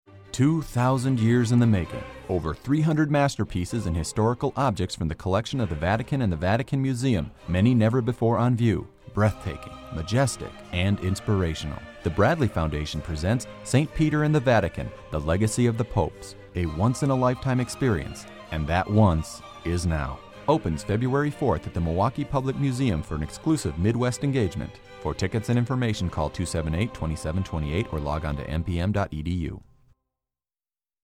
St. Peter and the Vatican Radio Commercial